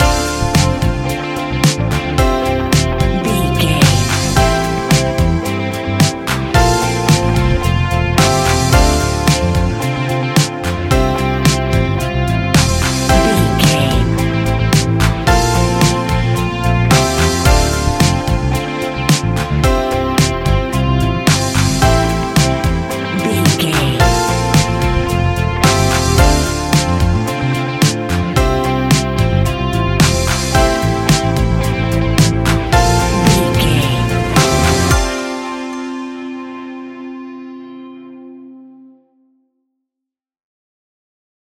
Ionian/Major
ambient
new age
downtempo
pads